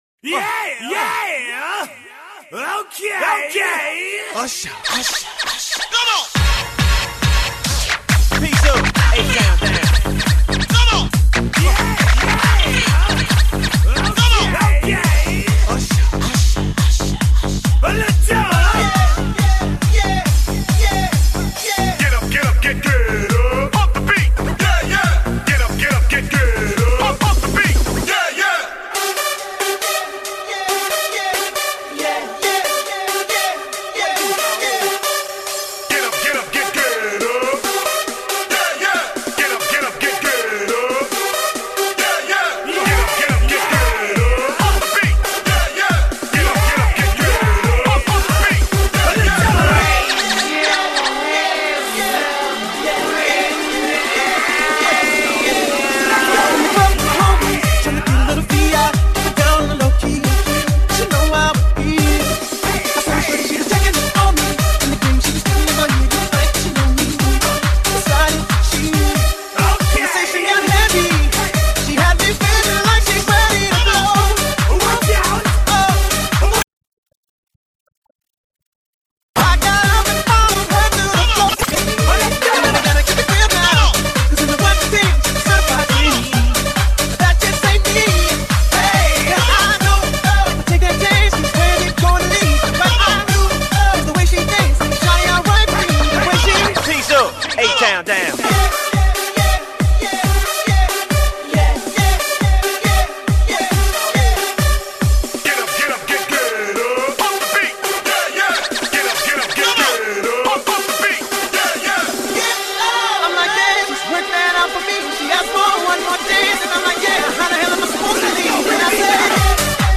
dance/electronic